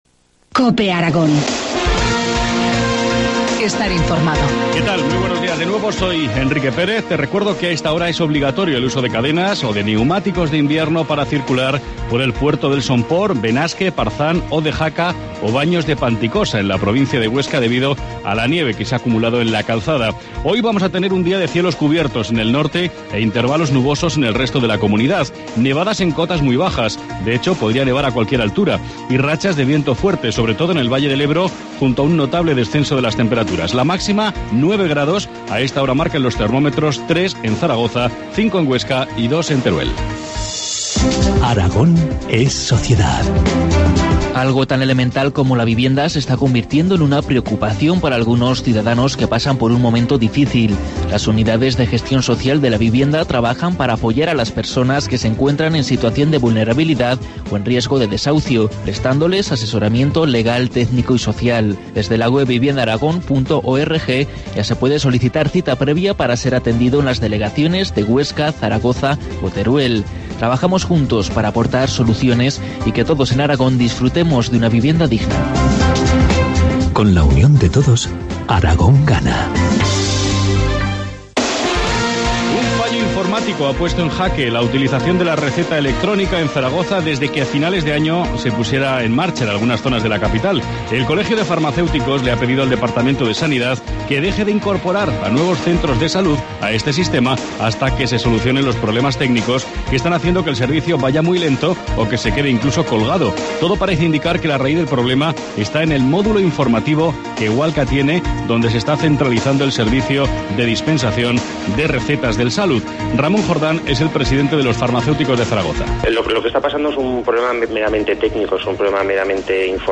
Informativo matinal, miércoles 13 de marzo, 8.27 horas